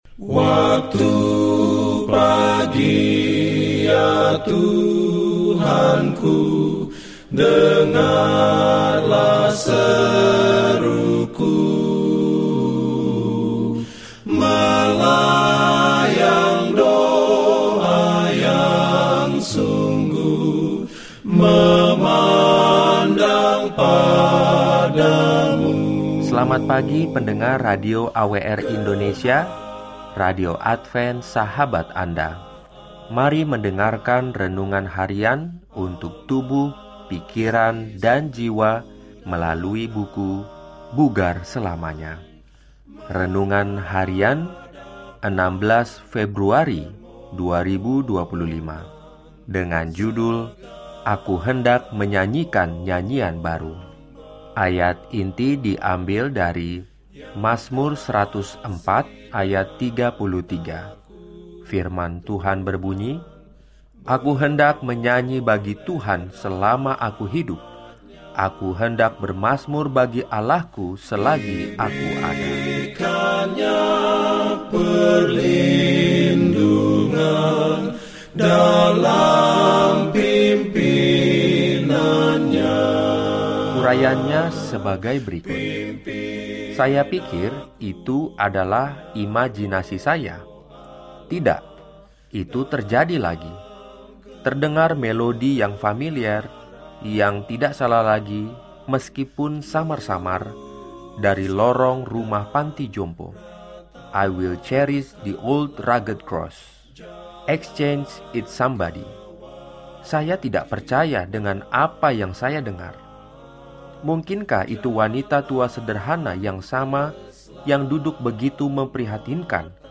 Daily Spiritual Devotional in Indonesian from Adventist World Radio